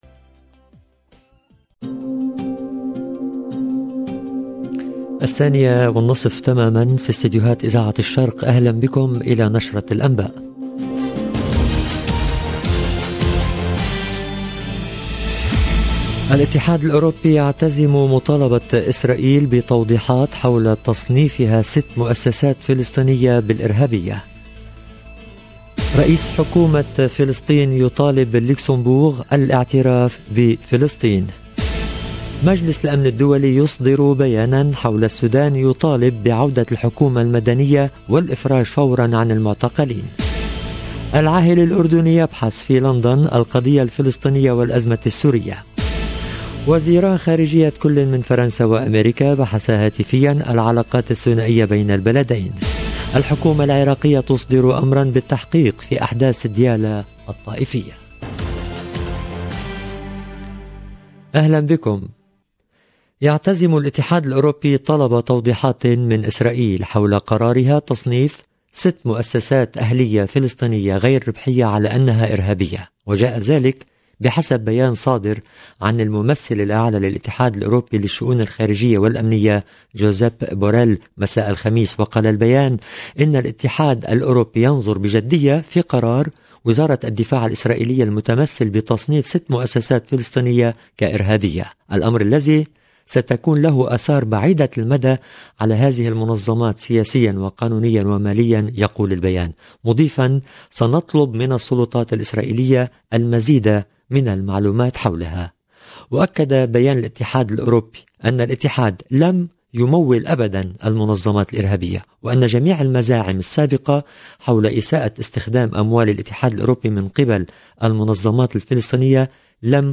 LE JOURNAL DE 14H30 EN LANGUE ARABE DU 29/10/2021
EDITION DU JOURNAL EN LANGUE ARABE